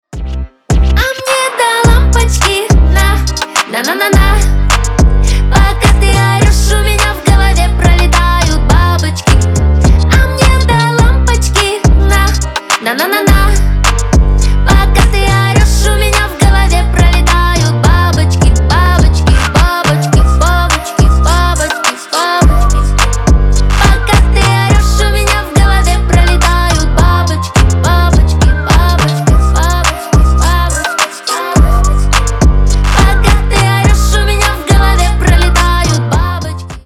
бесплатный рингтон в виде самого яркого фрагмента из песни
Поп Музыка
громкие